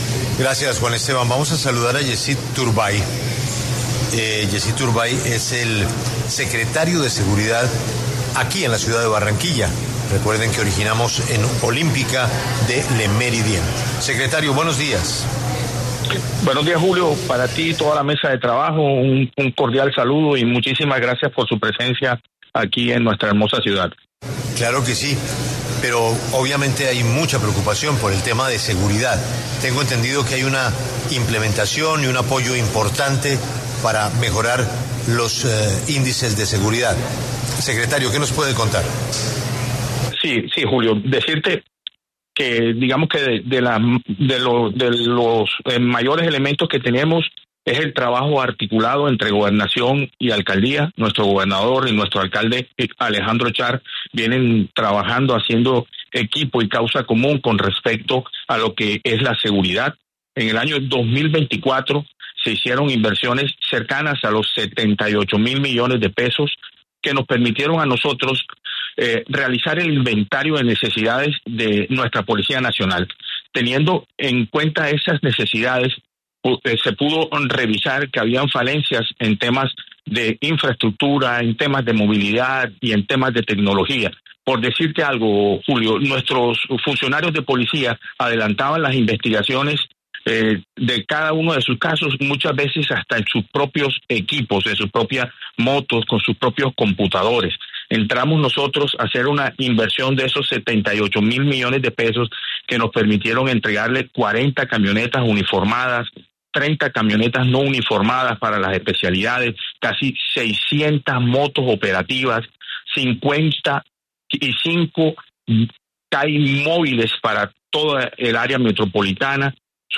El secretario de Seguridad de Barranquilla, Yesid Turbay, conversó en La W sobre la inversión que se está haciendo desde la Alcaldía para la fuerza pública.